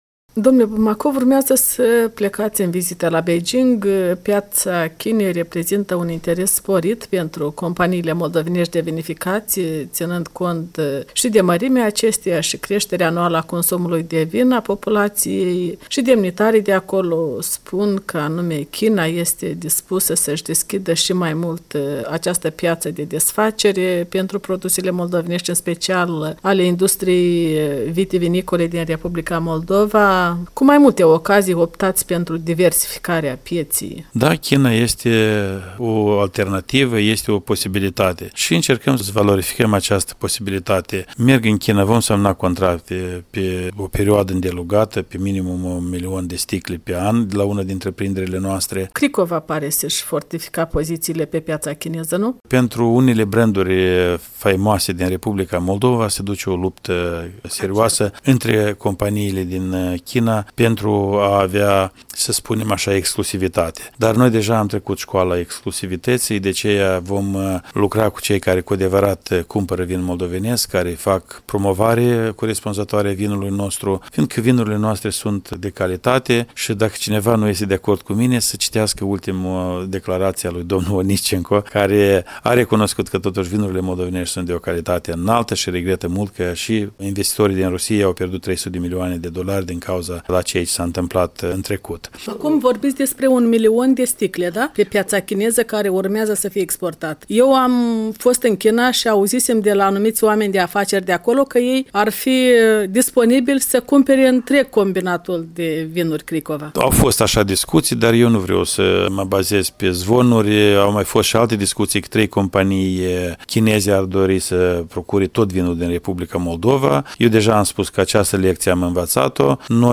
Interviu cu ministrul agriculturii Vasile Bumacov.